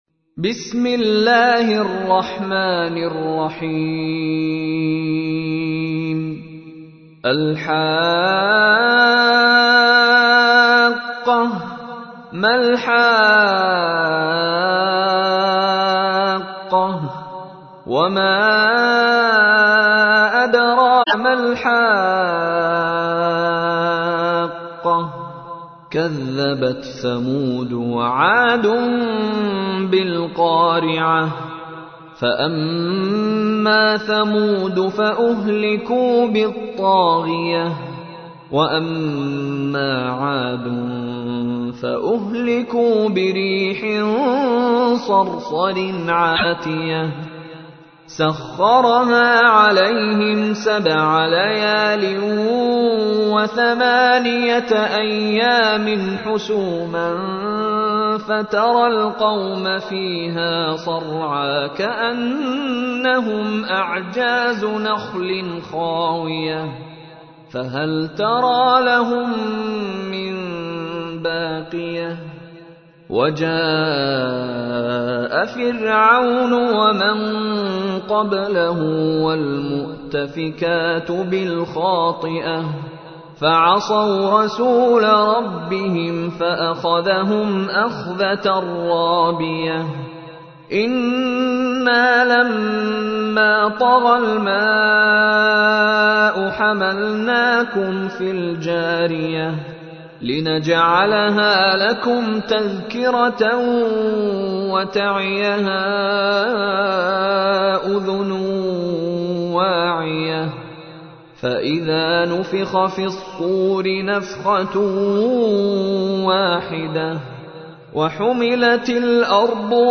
تحميل : 69. سورة الحاقة / القارئ مشاري راشد العفاسي / القرآن الكريم / موقع يا حسين